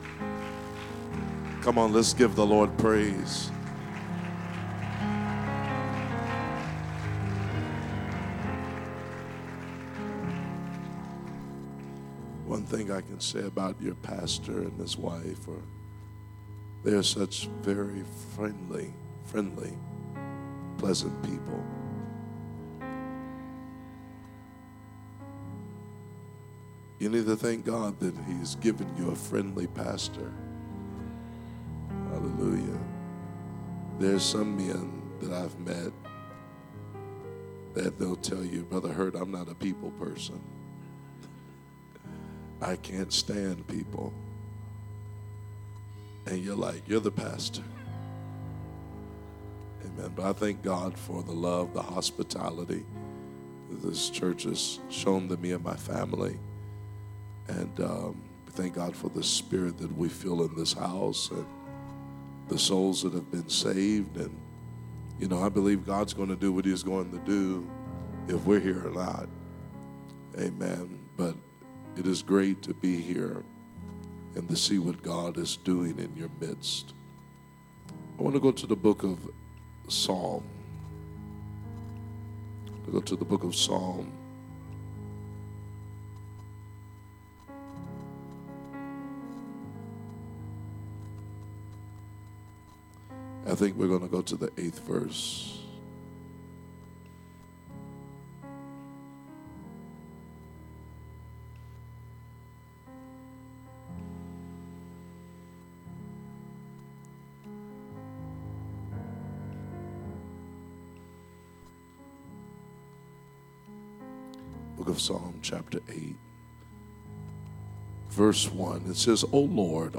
Sermons | Elkhart Life Church
Wednesday Service - Part 4